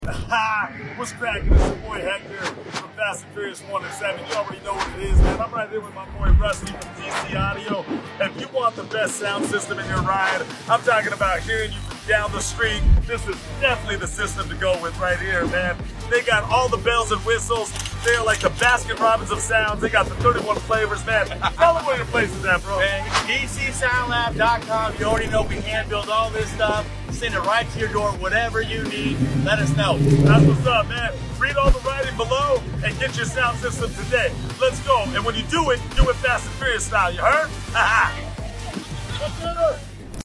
at Racewarz 2025